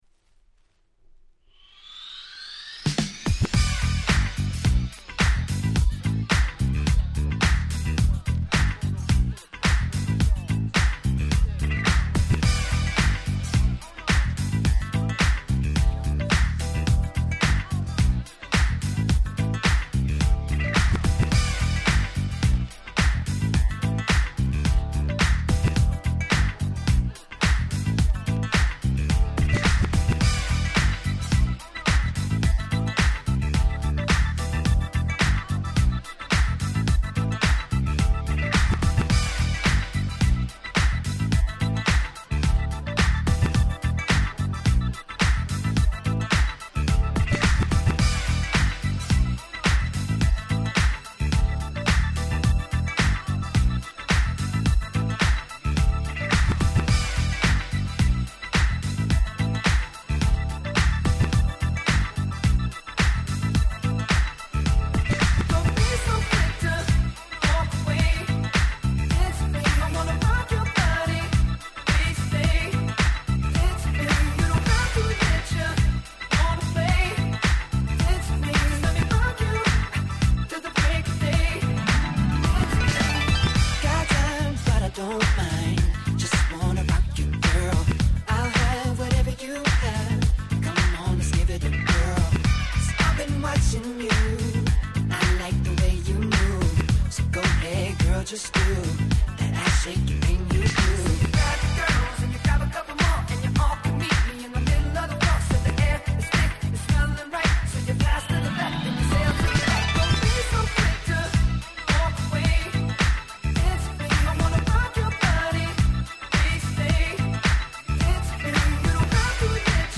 ※試聴ファイルは別の盤から録音してございます。
Disco/Boogieの要素をふんだんに取り入れた非常に素敵なRemixです。